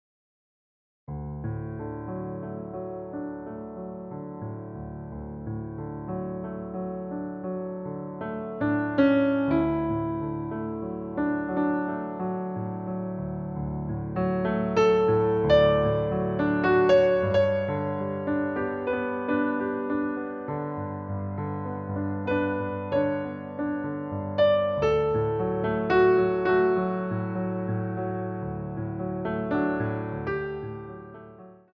Adage